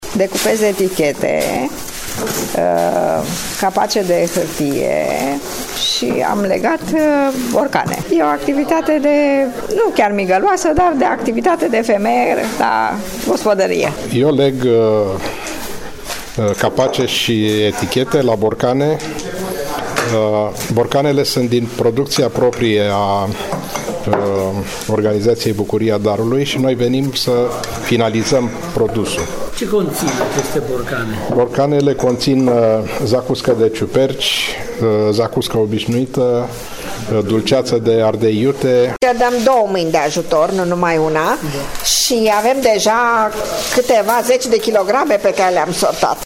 BUCURIA-DARULUI-VOXURI.mp3